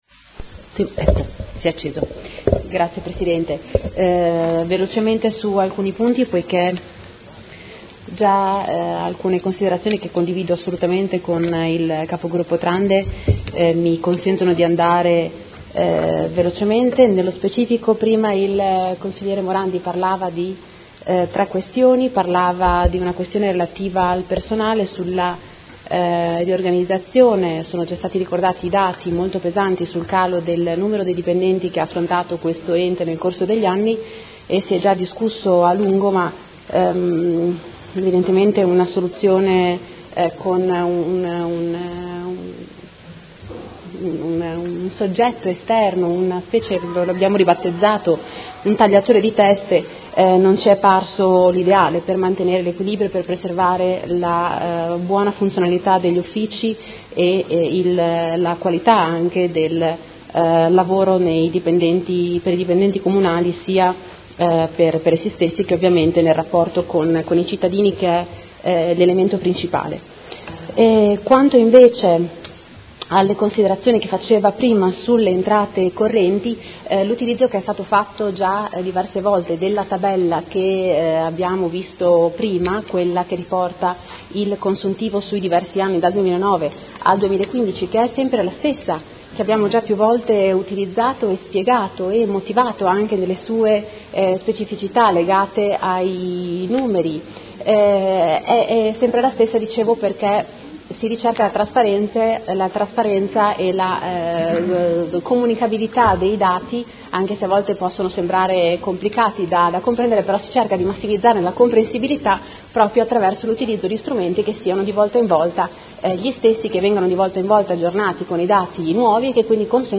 Seduta del 28/04/2016. Proposta di deliberazione: Rendiconto della gestione del Comune di Modena per l’esercizio 2015 – Approvazione.